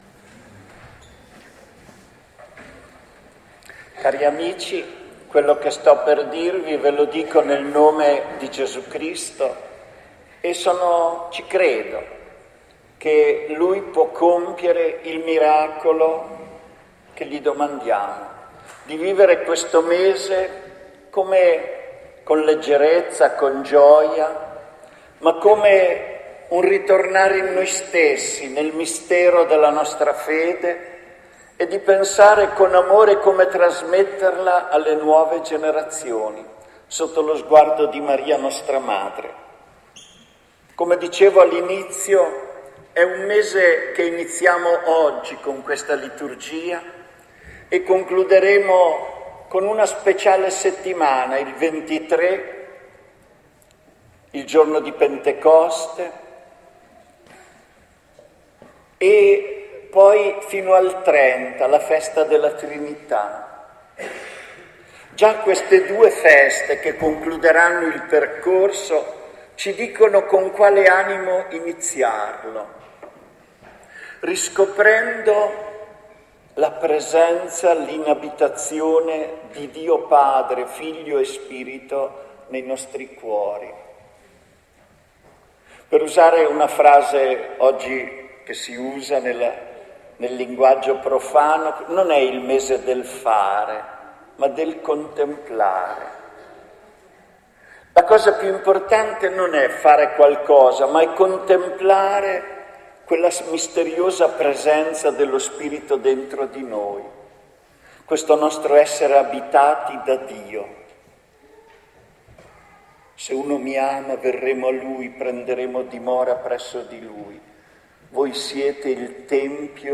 Ecco l’omelia della messa delle ore 11 di oggi 2 maggio 2010